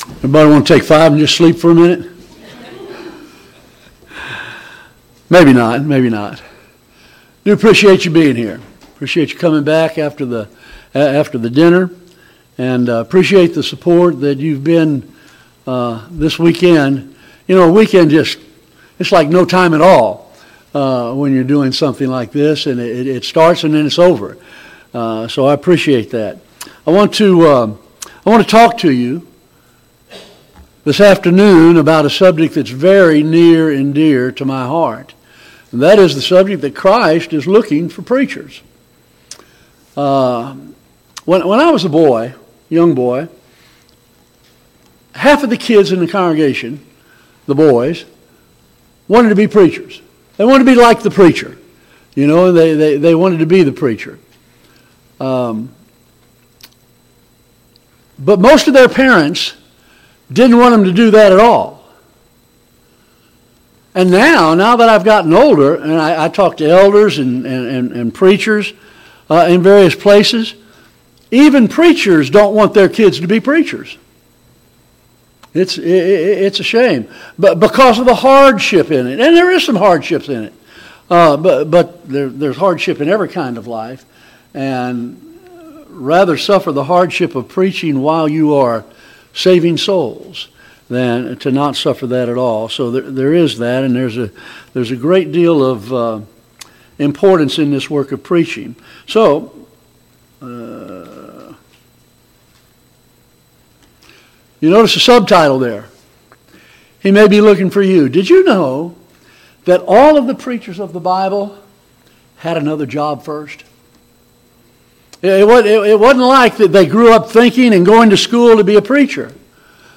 2025 Fall Gospel Meeting Service Type: Gospel Meeting « 4.